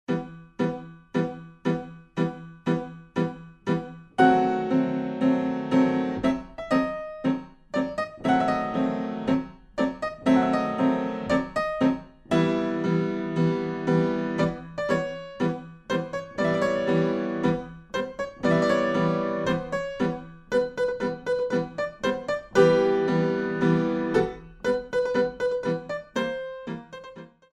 Allegretto - Moderato - Adagio